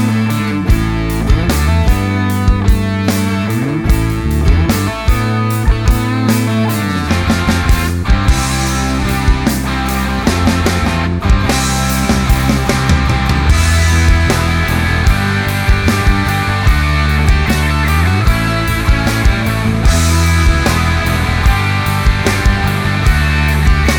End Cut Down Rock 4:17 Buy £1.50